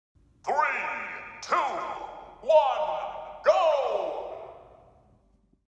Smash Ultimate 3 2 1 Sound Effects Free Download